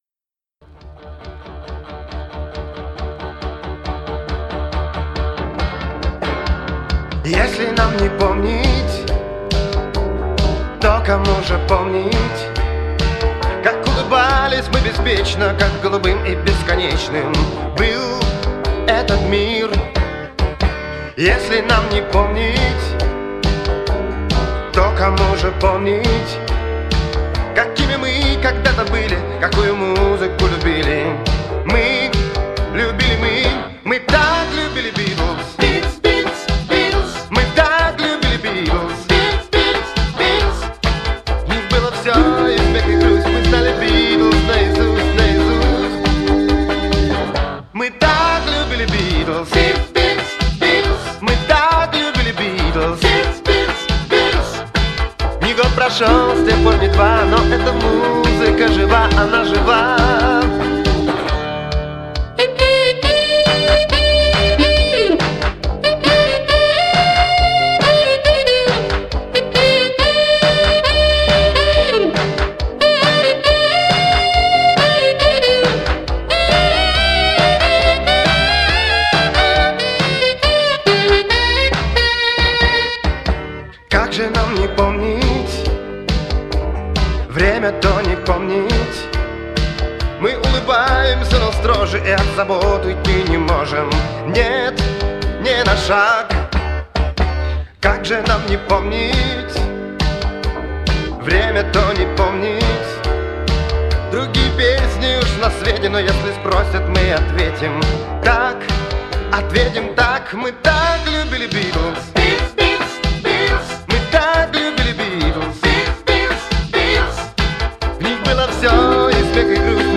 Переслушал сотни инструментальных фантазий, НО ЭТА ,,,,!